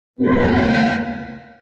58aea60d01 Divergent / mods / Soundscape Overhaul / gamedata / sounds / monsters / poltergeist / attack_8.ogg 10 KiB (Stored with Git LFS) Raw History